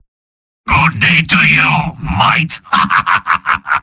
Robot-filtered lines from MvM.
{{AudioTF2}} Category:Spy Robot audio responses You cannot overwrite this file.